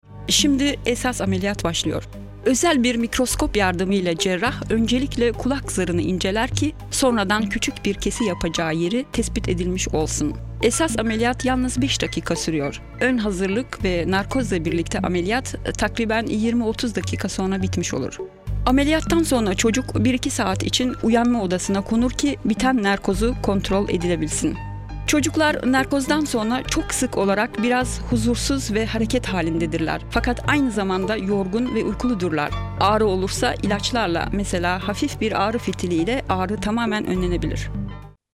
Sprecherin türkisch. Muttersprachliches Istanbuler Hochtürkisch.
Sprechprobe: Industrie (Muttersprache):
turkish female voice over artist